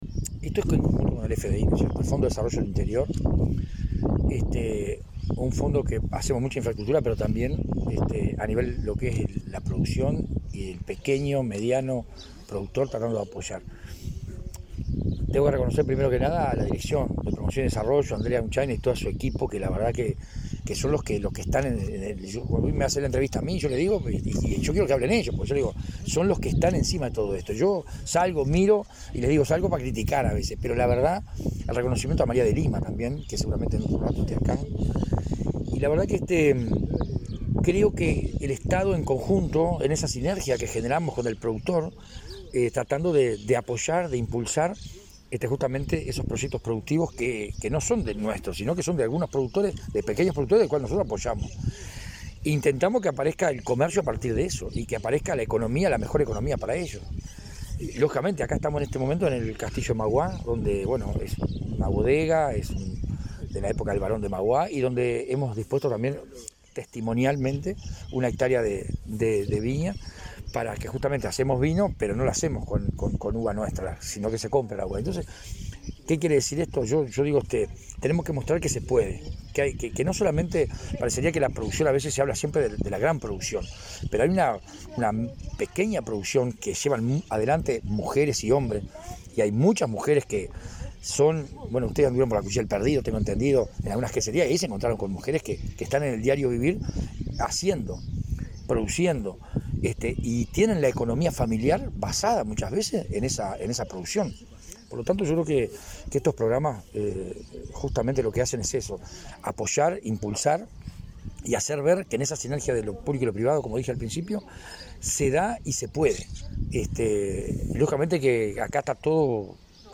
Entrevista al intendente de Soriano, Guillermo Besozzi